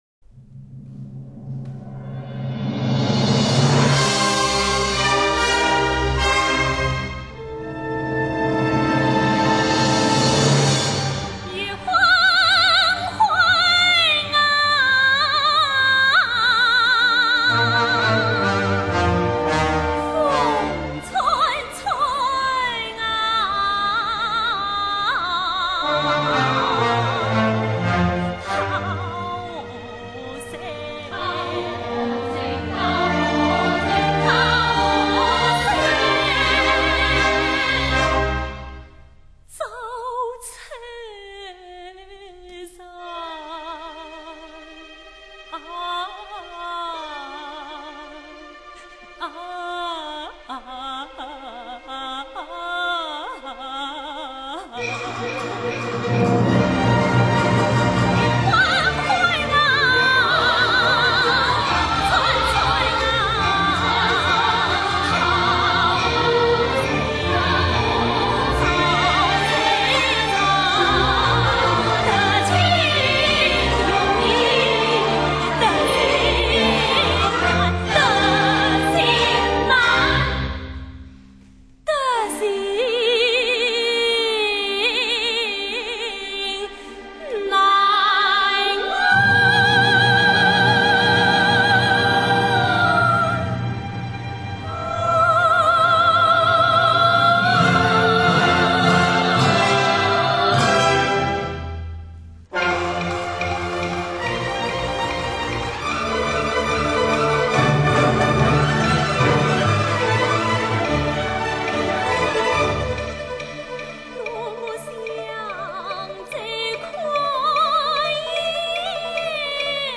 作品后半部分第四和第五乐章连续演出戏剧表现力极强，独唱、合唱和乐队表现得都非常震撼人心。
越剧清唱剧